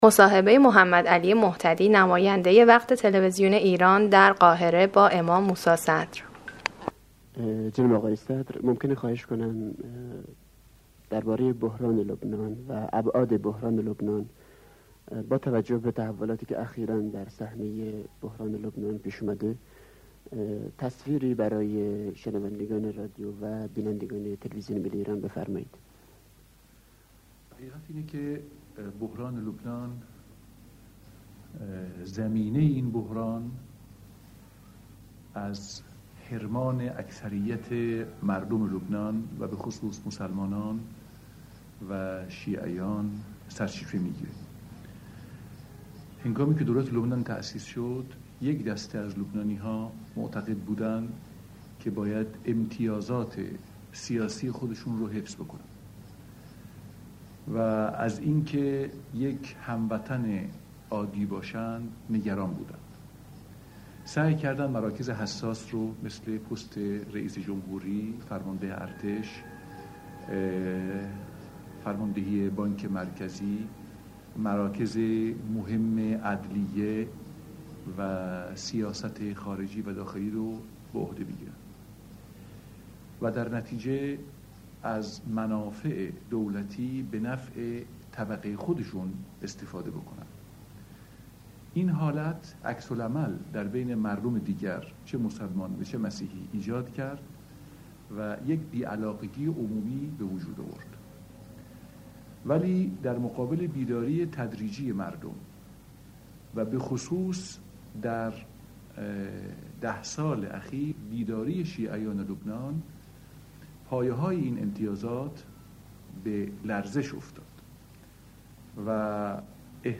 مصاحبهٔ
از دقیقه ۱۳ به بعد، فایل تکرار میشود